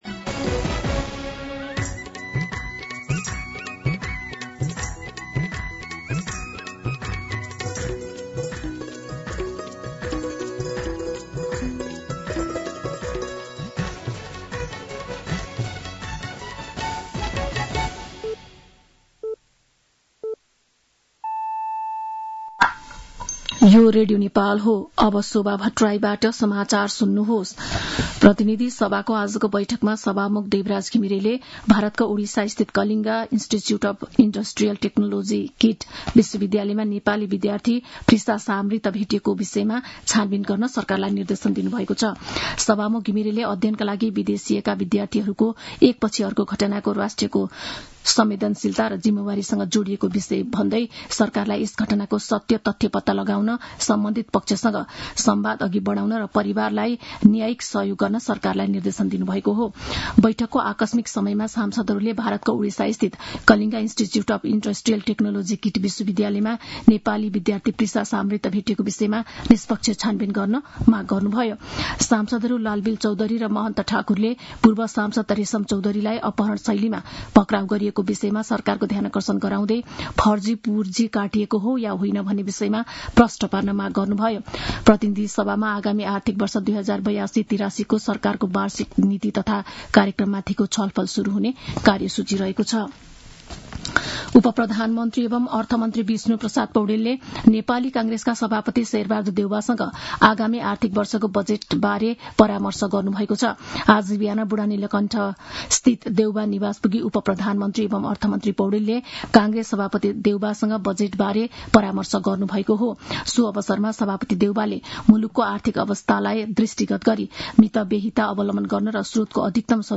दिउँसो १ बजेको नेपाली समाचार : २२ वैशाख , २०८२
1pm-Nepali-News-1-22.mp3